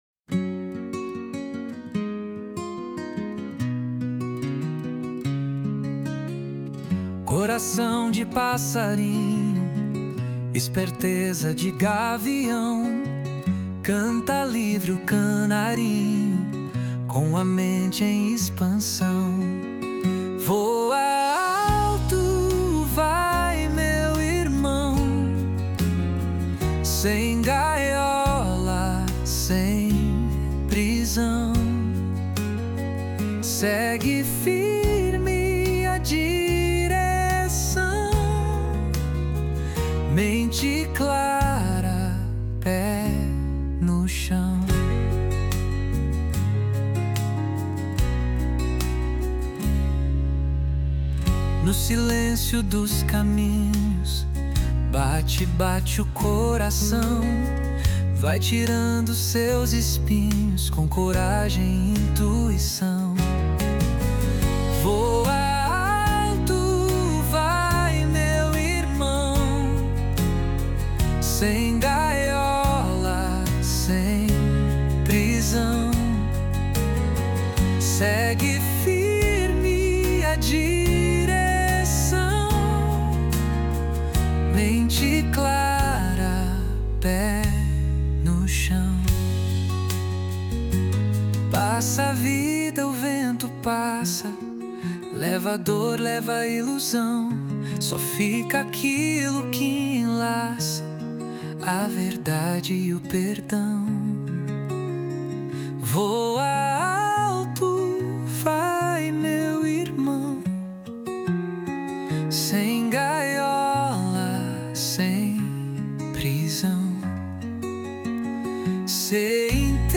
Agora, além de lê-lo, você pode também ouvir sua versão musicada, criada com carinho para transformar palavras em melodia.